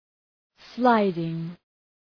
Προφορά
{‘slaıdıŋ}